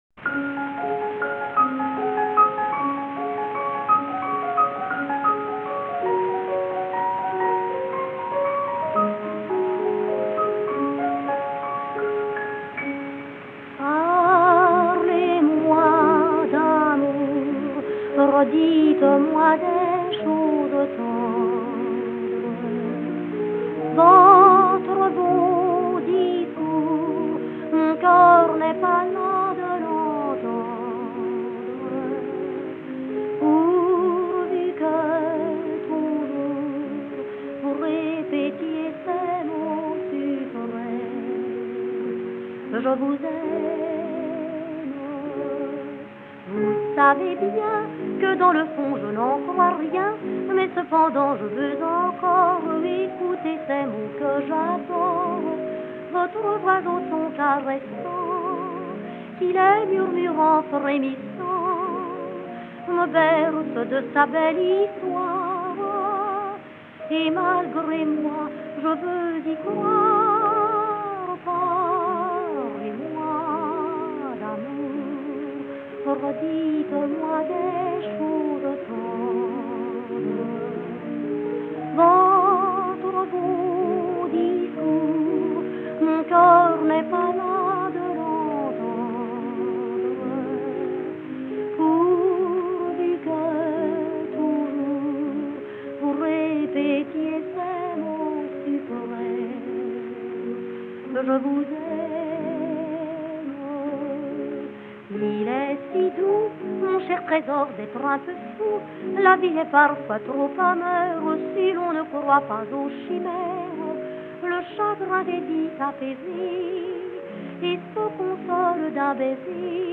专辑类型：Chanson
法国香颂歌词优美旋律诱人、甜美浪漫。
融合了传统香颂与现代感十足的爵士曲风，并承袭一贯的法国情调，全方位浪漫呈现了法式的酒馆文化。